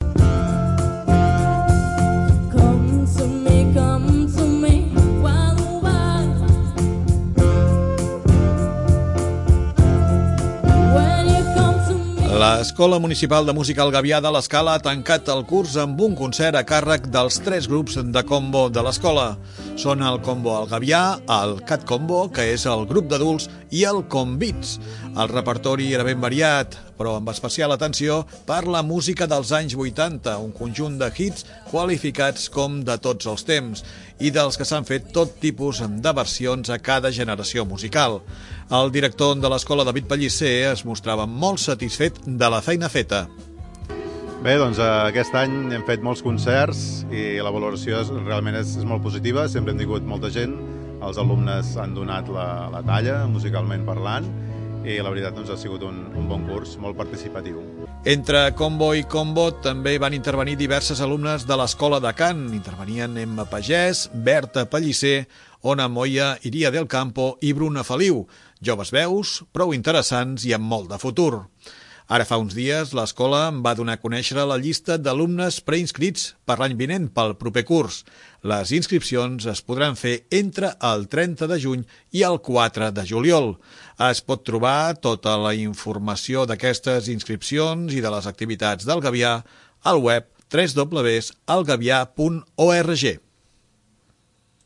Els grups de combos de l'Escola de Música el Gavià han celebrat el concert de final de curs amb una actuació a Plaça Víctor Català. Hi han participat també alguns dels solistes de l'escola de cant.
L'Escola Municipal de Música El Gavià ha tancat el curs amb un concert a càrrec dels tres grups de combo de l'Escola.
El repertori era ben variat, però amb especial atenció per la música dels anys 80, un conjunt de hits qualificats com 'de tots els temps', i dels que s'han fet tots tipus de versions a cada generació musical.
Entre combo i combo també van intervenir diverses alumnes de l'escola de cant.